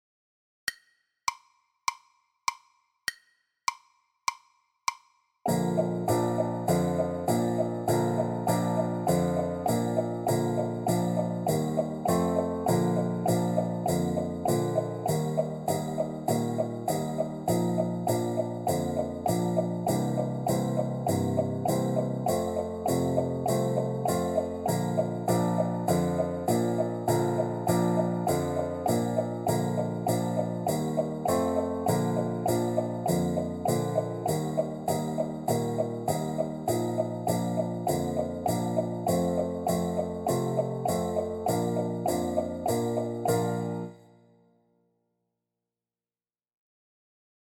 for Clarinet
accompaniment